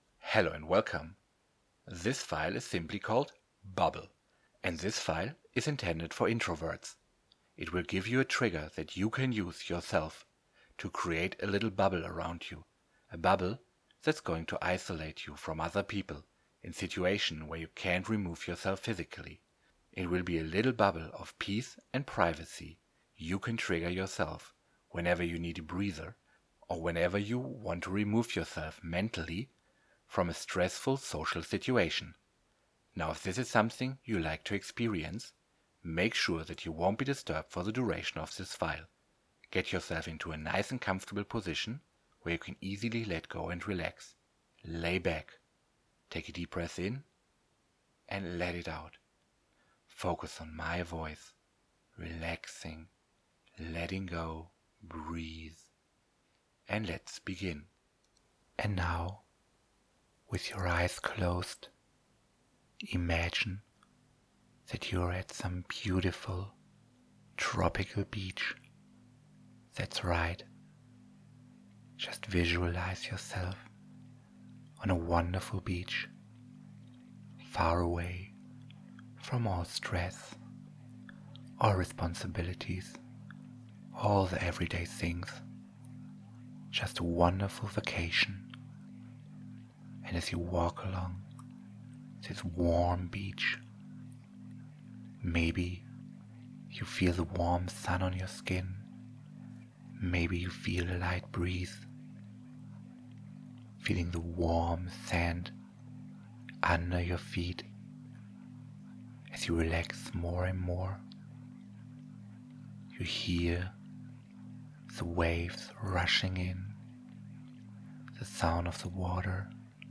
Bubble-Deep-Sea-Induction.mp3